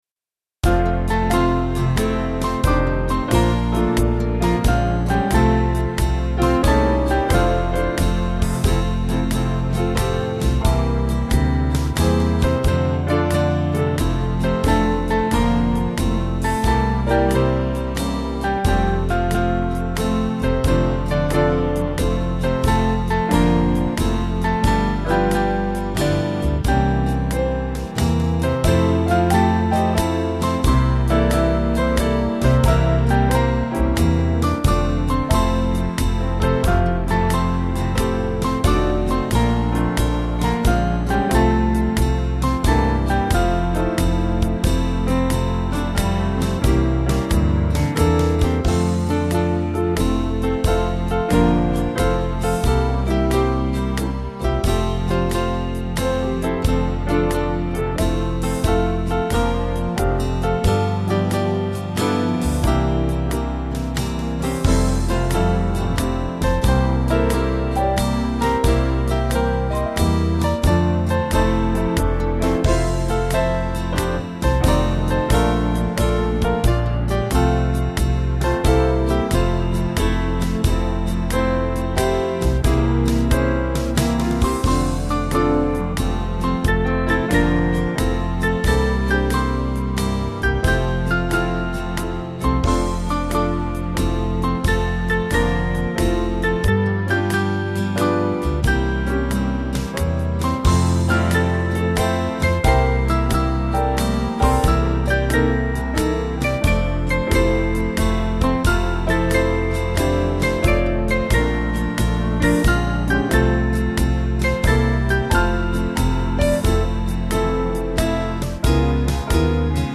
Swing Band